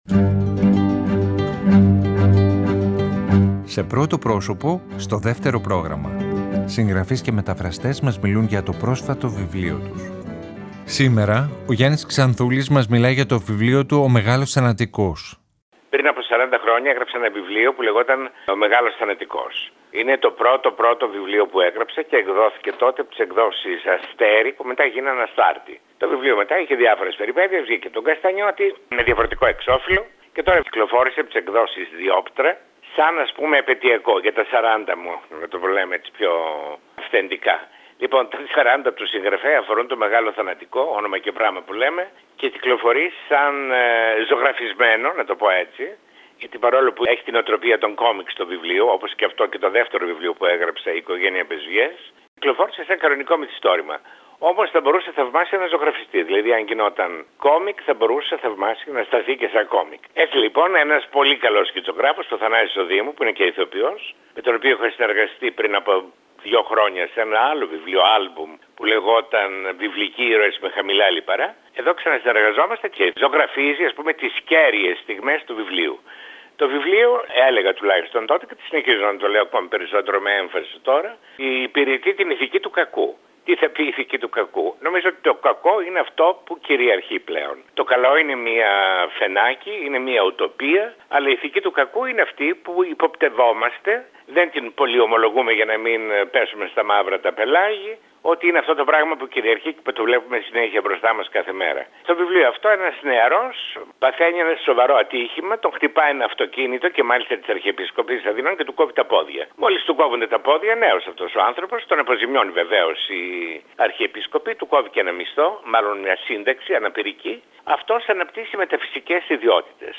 Σήμερα ο Γιάννης Ξανθούλης μας μιλάει για το βιβλίο του «Ο μεγάλος θανατικός». Το πρώτο μυθιστόρημα του συγγραφέα σε επετειακή έκδοση, 40 χρόνια μετά την πρώτη του κυκλοφορία.